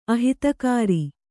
♪ ahitakāri